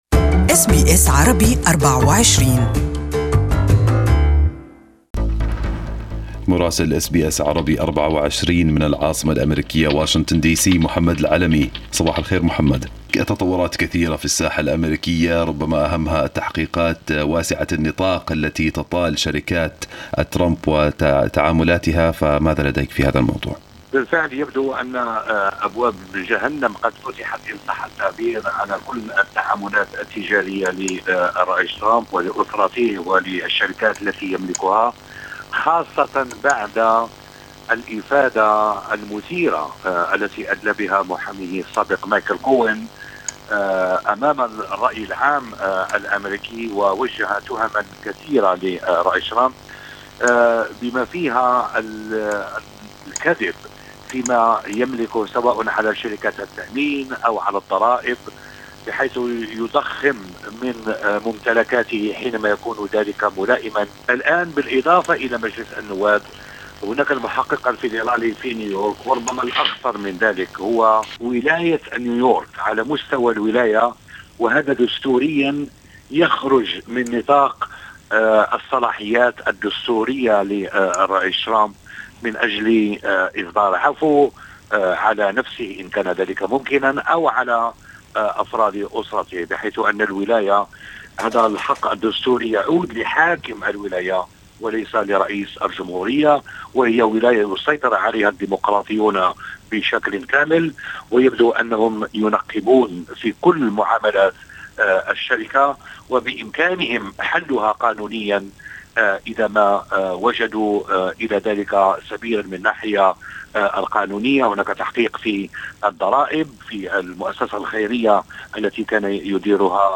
Listen to the full report from Washington in Arabic above